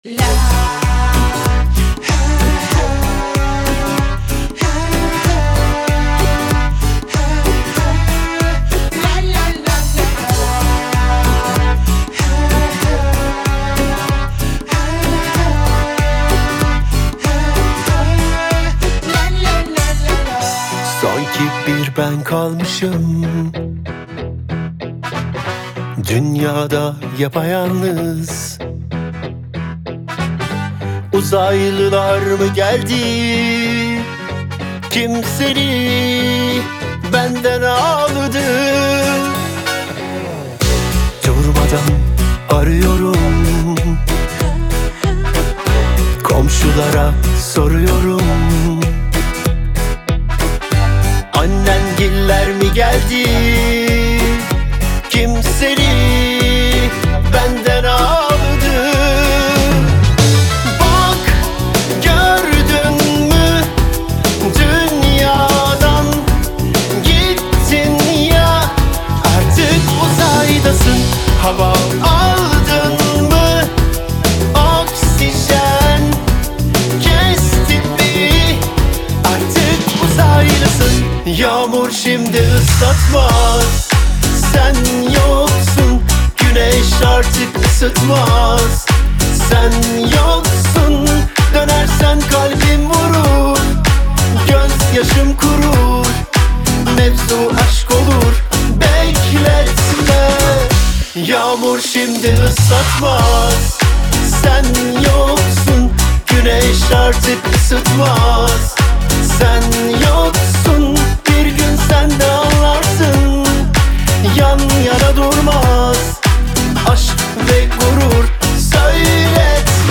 Трек размещён в разделе Турецкая музыка / Танцевальная.